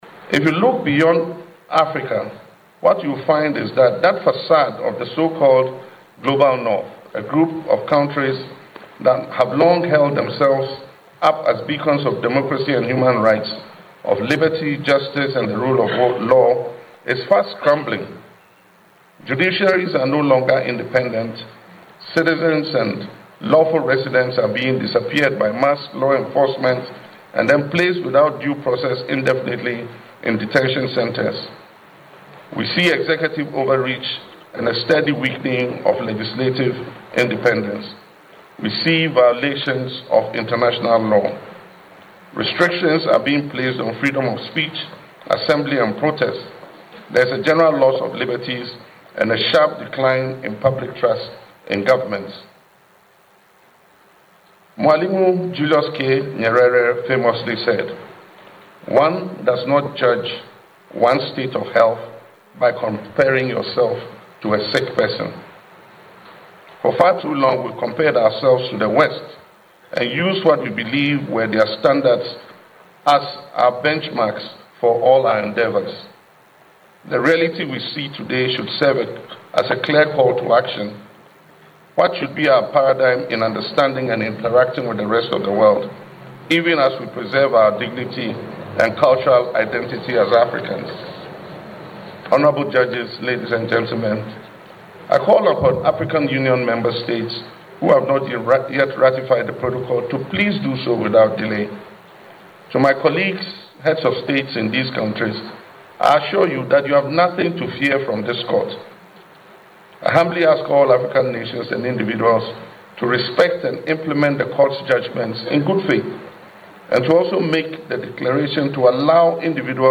Speaking in Arusha during events marking the anniversary of the African Court on Human and Peoples’ Rights, President Mahama pointed to trends such as executive overreach, weakening legislative independence, rising polarization, and increasing restrictions on civil liberties in regions traditionally viewed as long-standing champions of democracy.